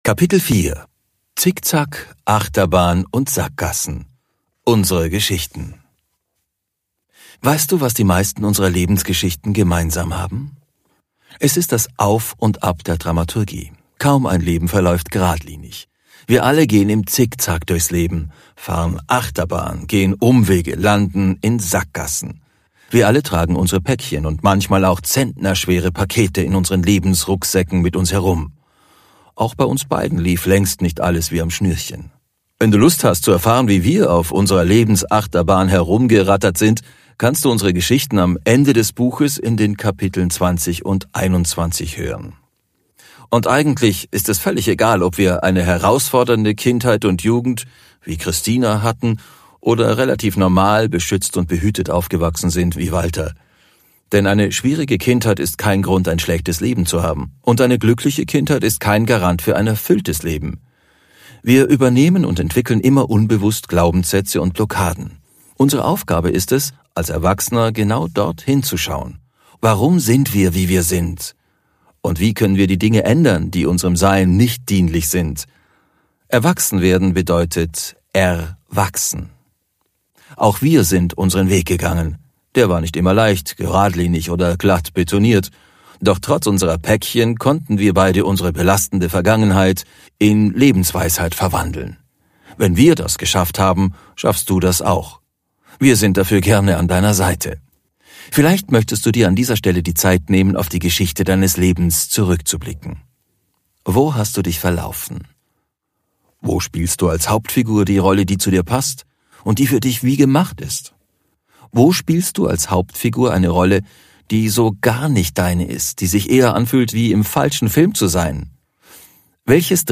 Hier kannst du einen Auszug aus dem Hörbuch hören